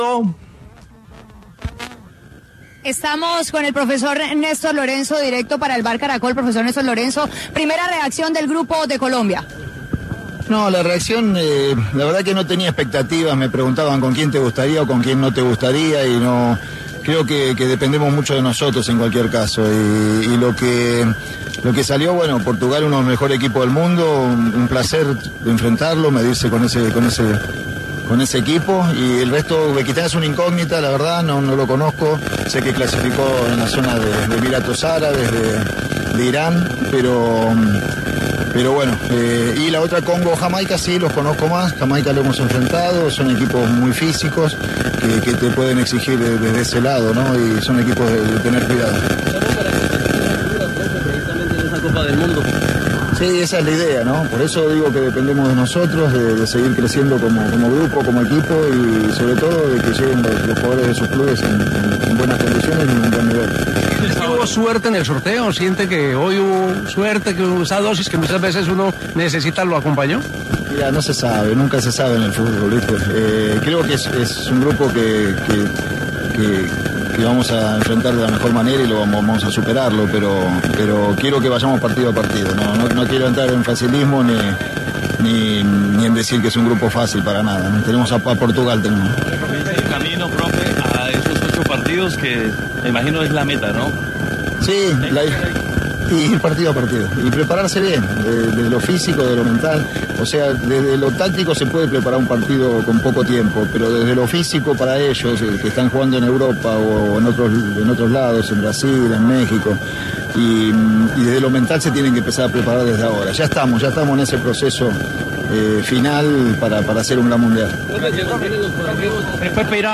Luego de que finalizó la ceremonia del sorteo en el Kennedy Center de Washington, en conversación con El VBar Caracol, habló el entrenador de la Colombia, Néstor Lorenzo, sobre las sensaciones y expectativas que tiene con el equipo de cara al Mundial del 2026, ya conociendo los rivales.